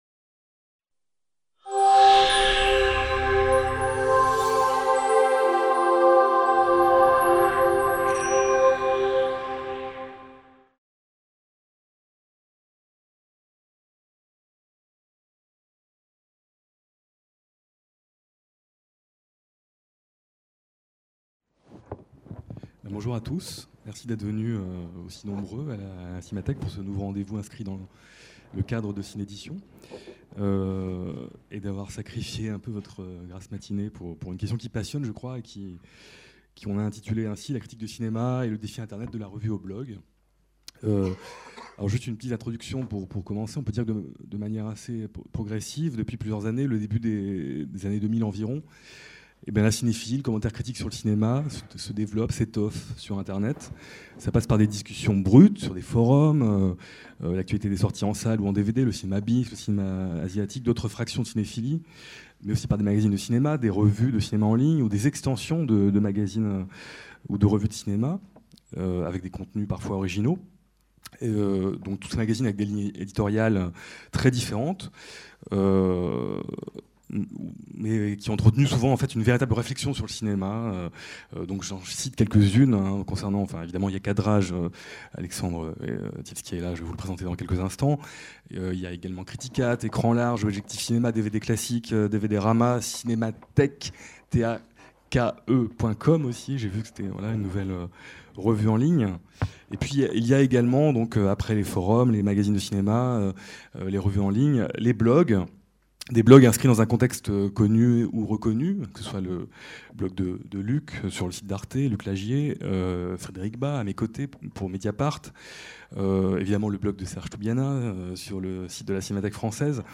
La critique de cinéma et le défi Internet : de la revue au blog ? Table ronde | Canal U